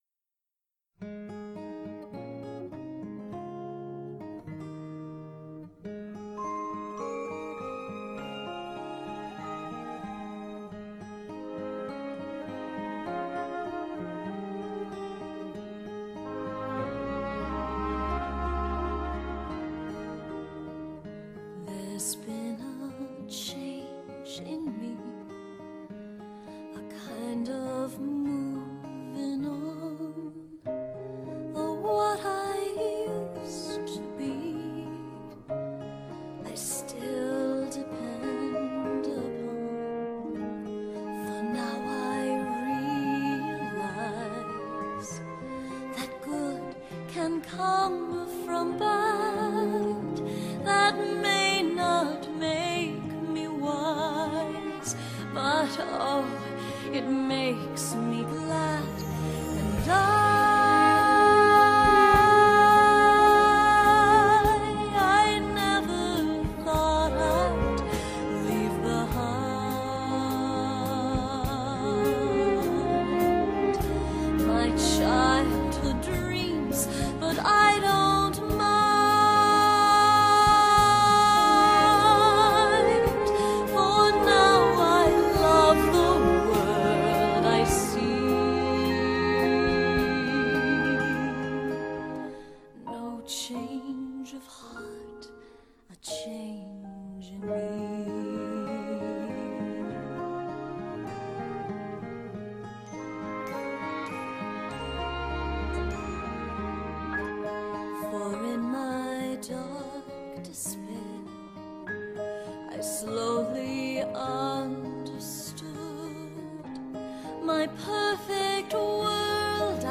Women's Musical Audition Selection - MP3